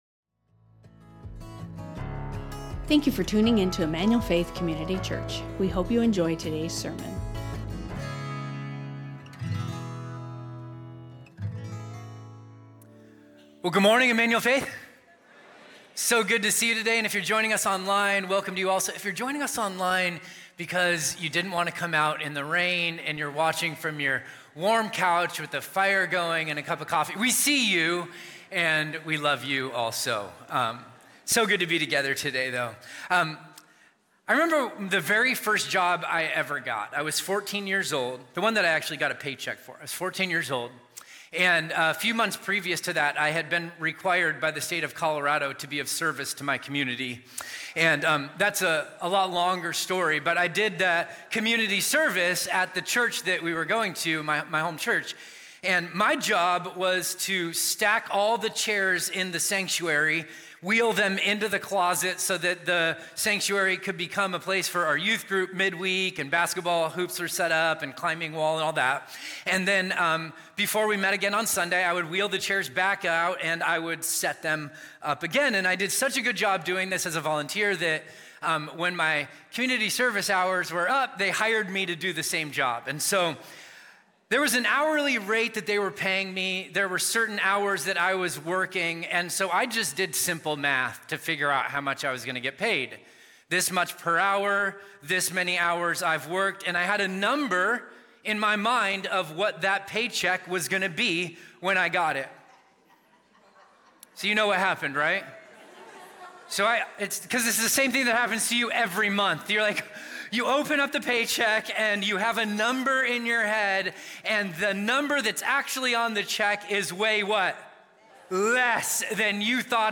Emmanuel Faith Sermon Podcast Entrusted | Matthew 25:14-30 Nov 17 2025 | 00:45:59 1x 00:00 / 00:45:59 Subscribe Share Spotify Amazon Music RSS Feed Share Link Embed